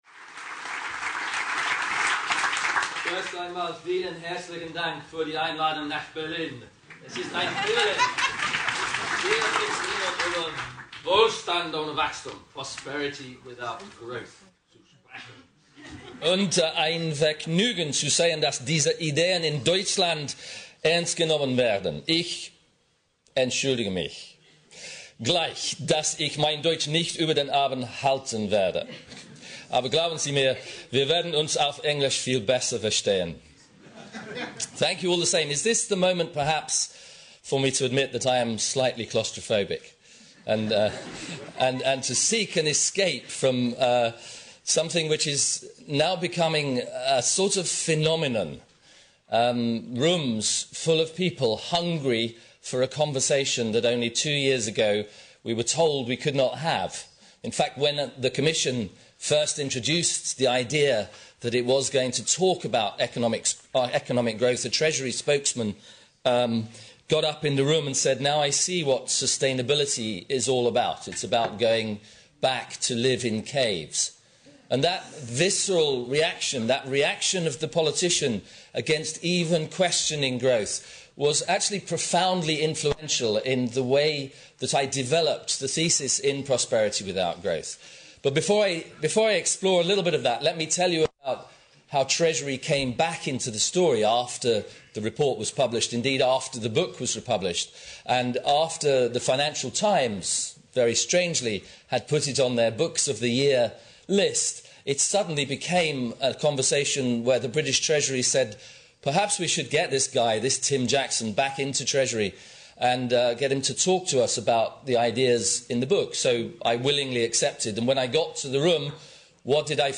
Diskussion mit Tim Jackson und Jürgen Trittin: Wohlstand ohne Wachstum - Heinrich-Böll-Stiftung